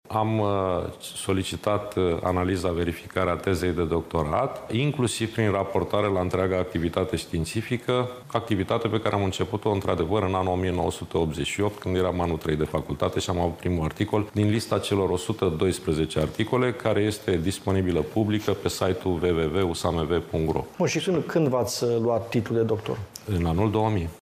Ministrul Educației, Sorin Cîmpeanu a spus, aseară, într-o emisiune la B1 TV că a sesizat încă de vineri Universitatea de Științe Agronomice din București să îi fie analizată teza de doctorat, iar lucrarea să fie trimisă pentru verificare și către CNATDCU.